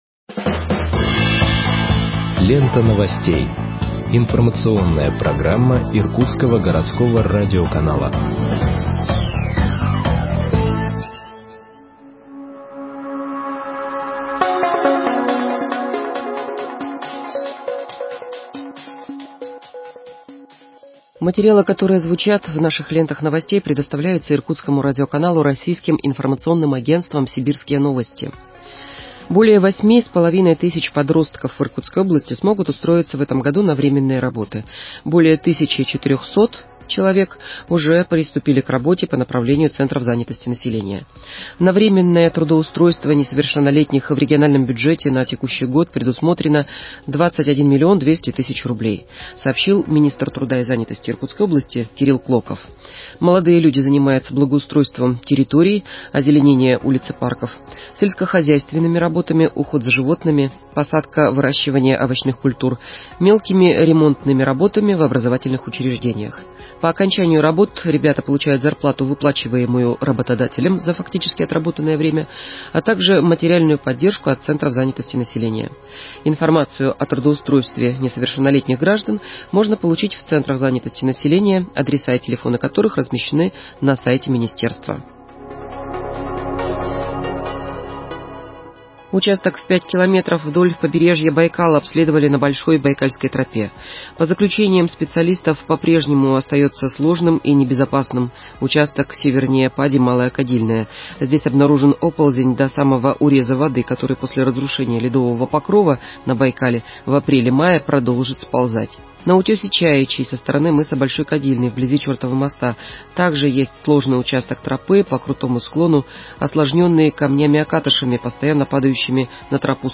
Выпуск новостей в подкастах газеты Иркутск от 12.04.2021 № 1